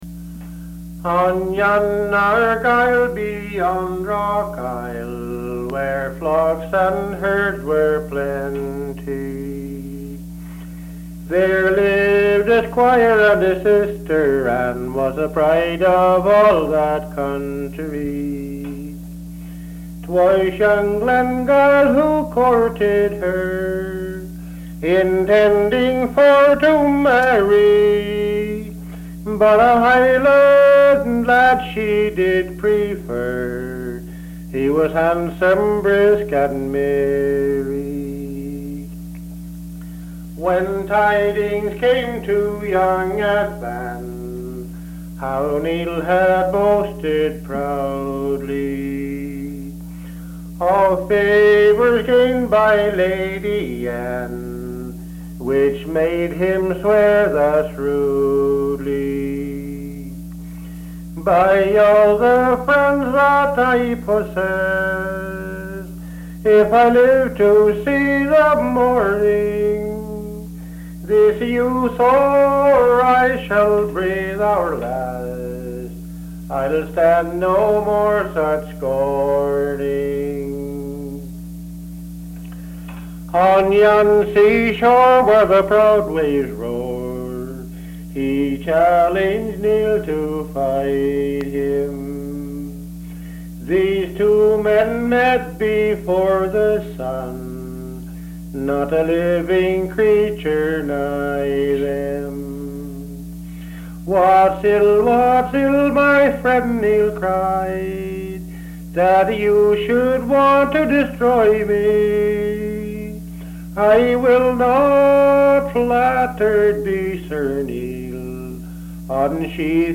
Town: Bridgewater, ME
This broadside ballad is of Scottish origin.